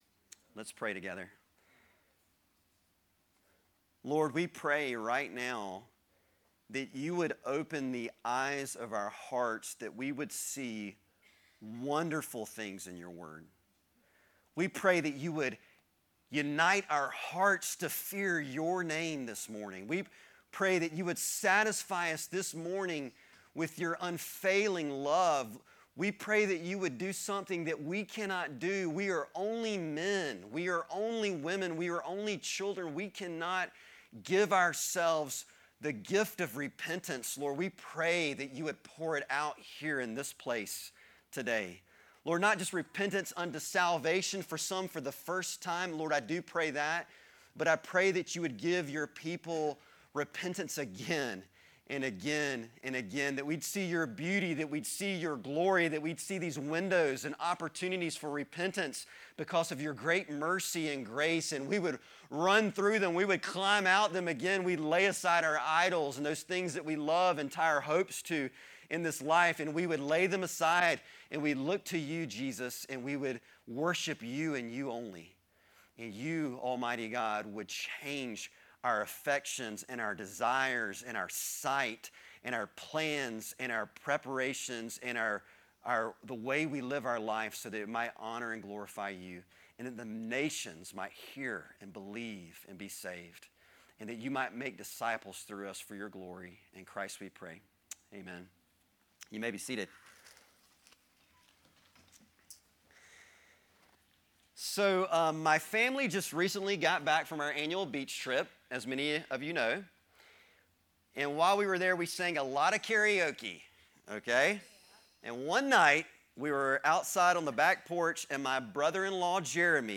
Sermons - Connection Fellowship